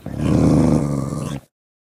Minecraft Version Minecraft Version snapshot Latest Release | Latest Snapshot snapshot / assets / minecraft / sounds / mob / wolf / classic / growl1.ogg Compare With Compare With Latest Release | Latest Snapshot
growl1.ogg